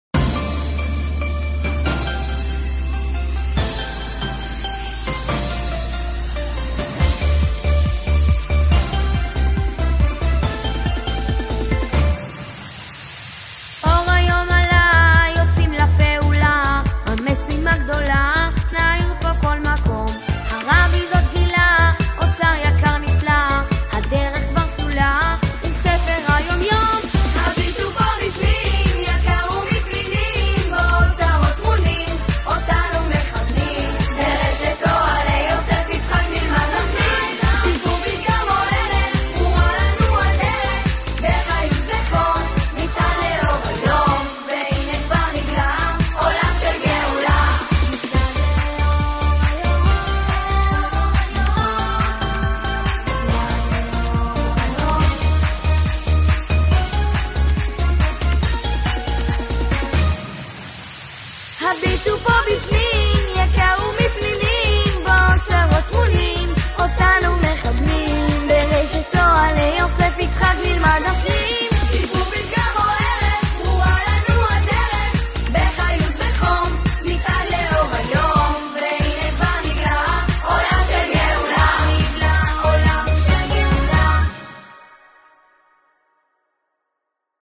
המנון שנתי- שמע- צלצול